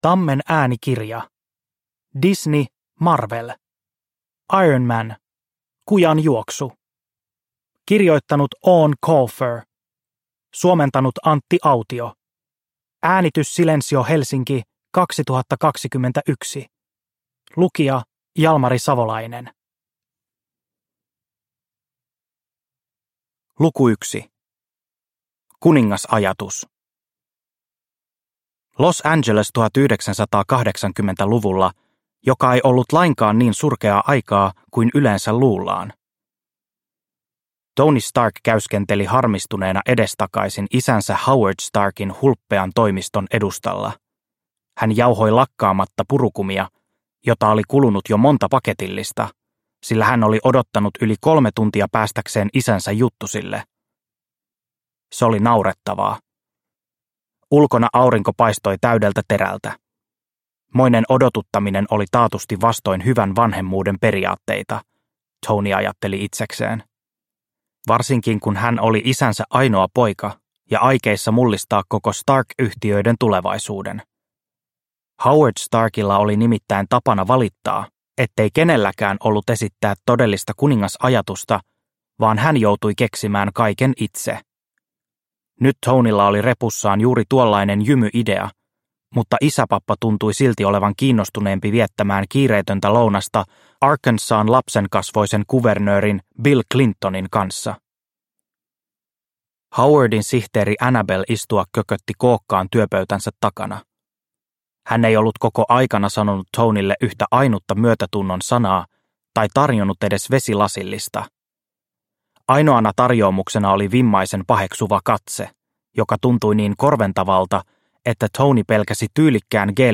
Kujanjuoksu – Ljudbok – Laddas ner